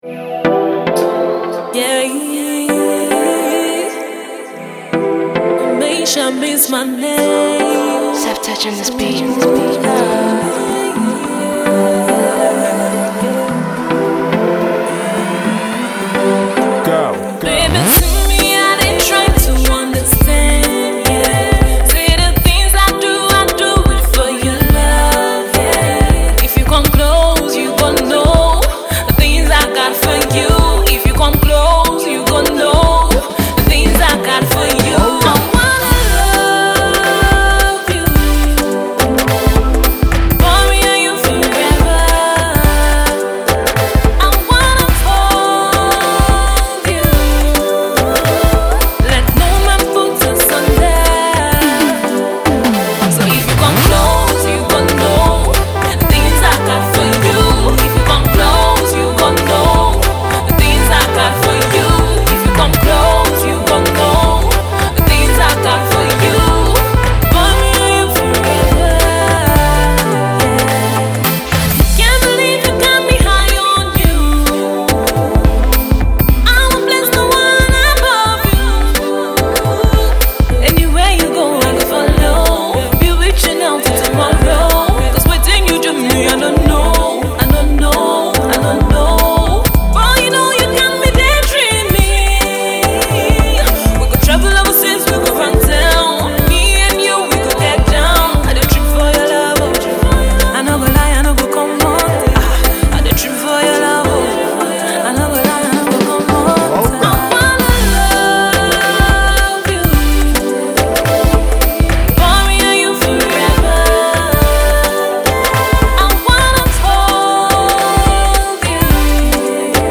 experimenting with hiphop, r&b to give it a unique flavor